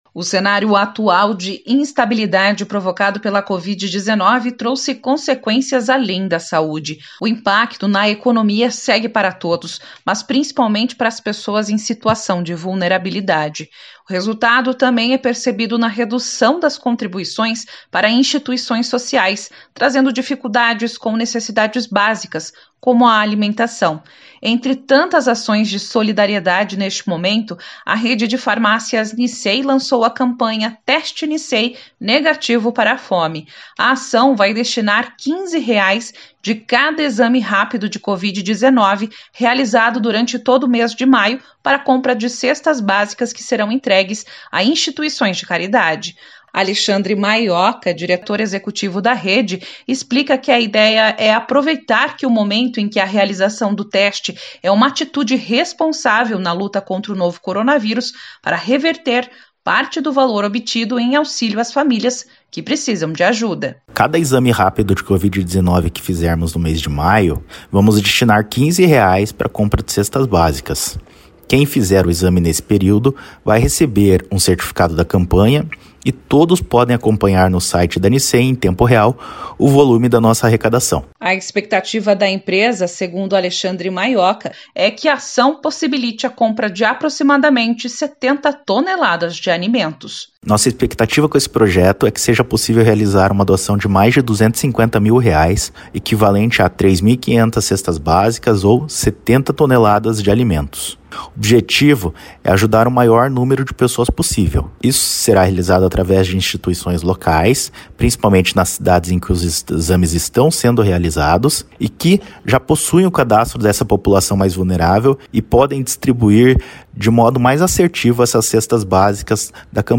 No Paraná, a Rede de Farmácias Nissei vai destinar parte do valor dos testes para a compra de cestas básicas, que serão entregues a instituições de caridade. Saiba mais na reportagem da série “Vale Muito” de hoje.